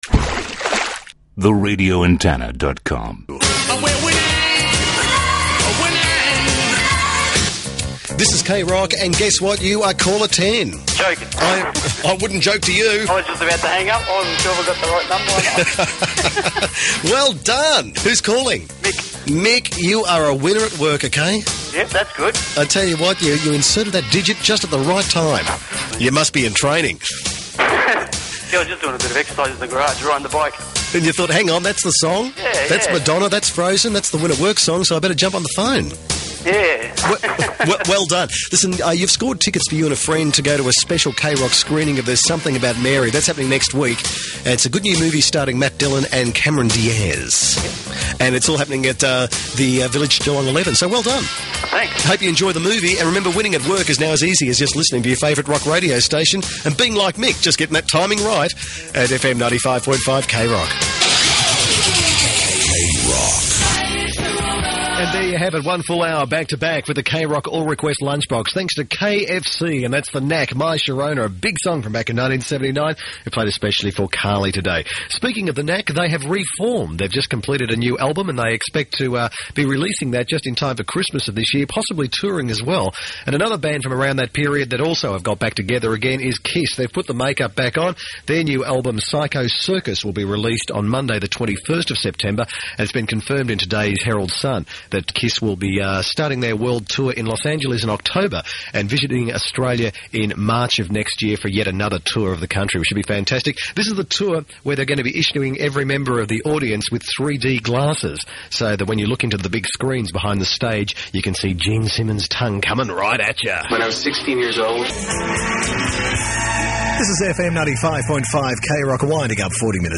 RA Aircheck – KROCK